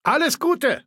Datei:Maleold01 ms06 goodbye 000284bd.ogg
Fallout 3: Audiodialoge